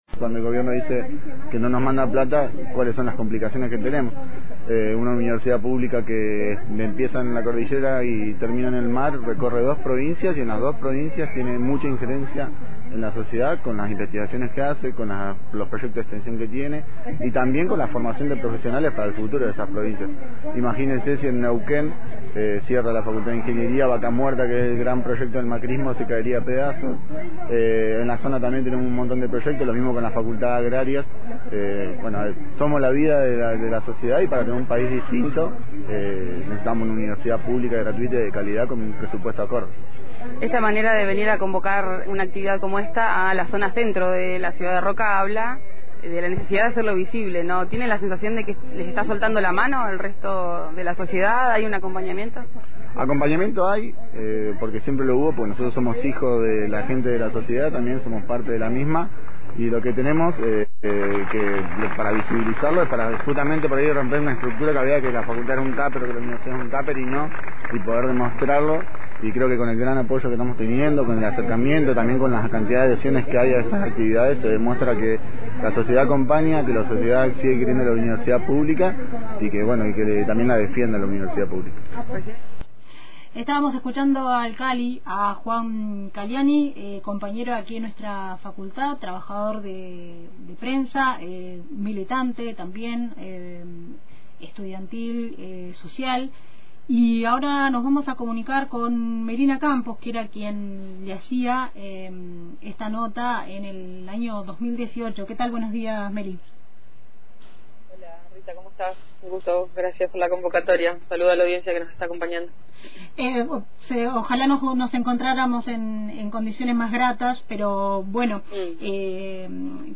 en el marco de una marcha por la defensa de la Universidad Pública